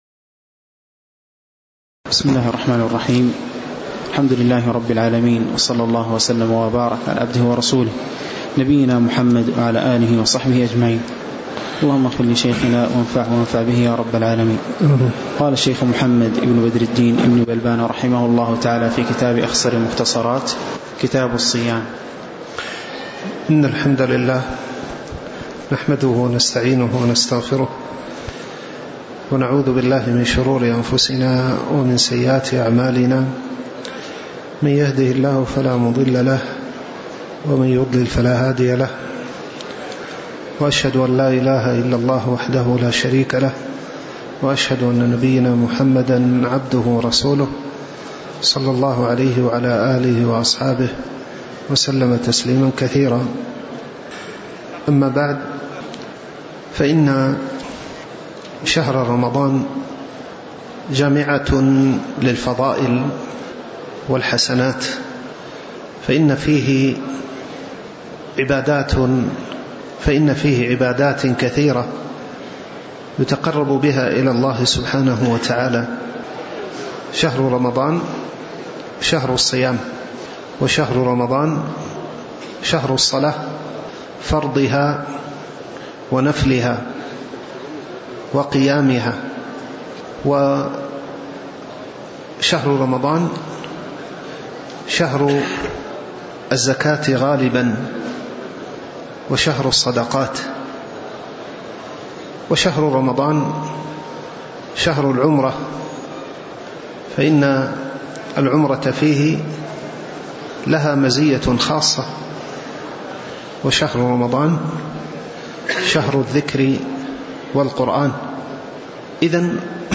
تاريخ النشر ٢٢ شعبان ١٤٣٩ هـ المكان: المسجد النبوي الشيخ